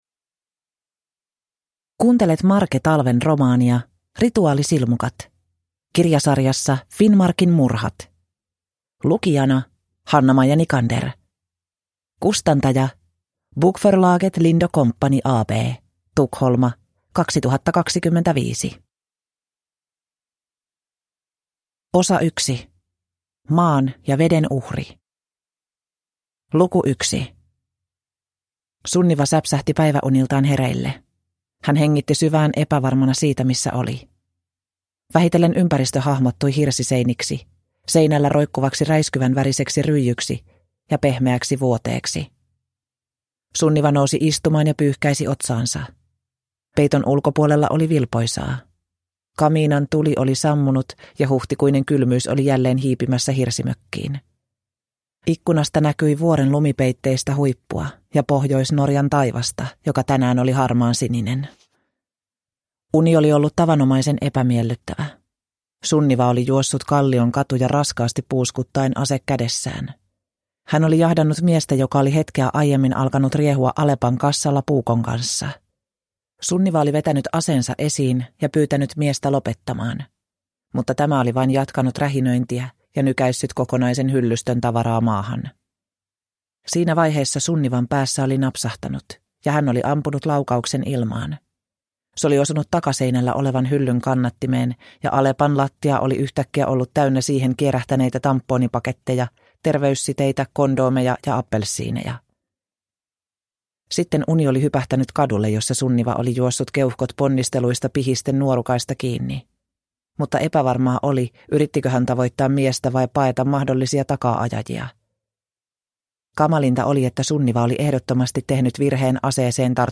Rituaalisilmukat – Ljudbok